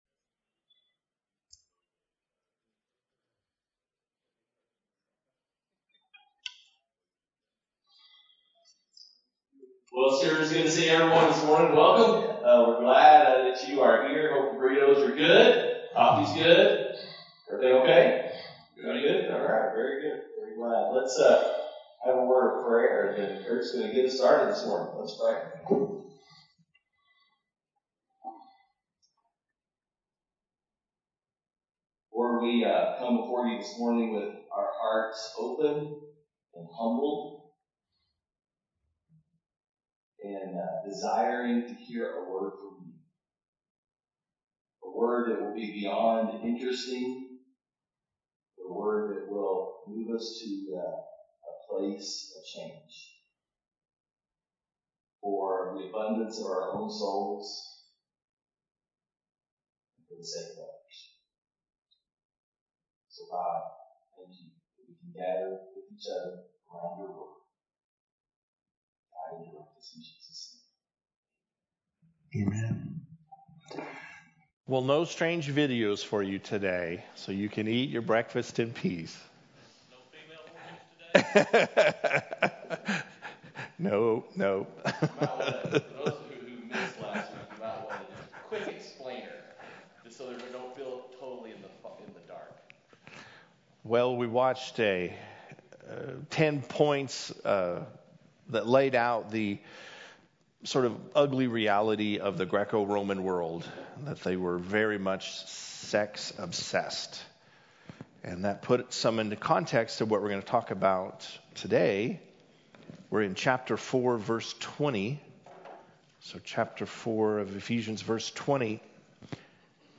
Men’s Breakfast Bible Study 11/10/20